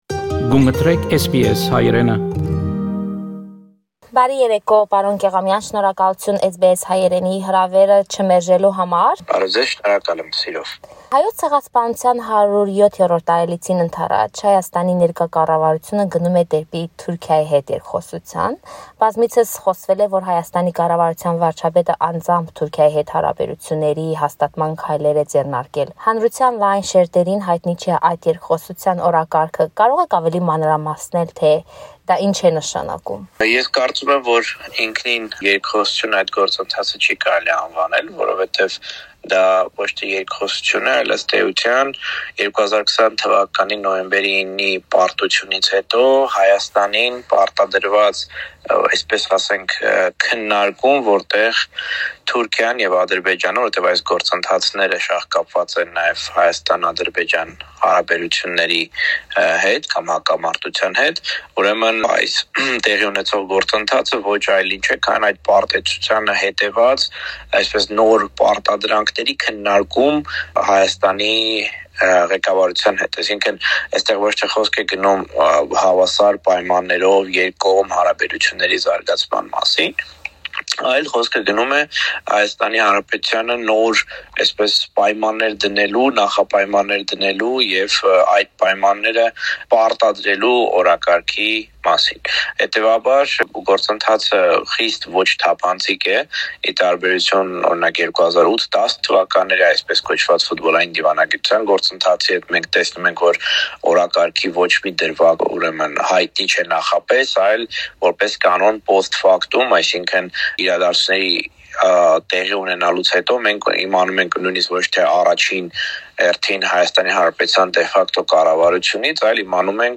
Հարցազրույց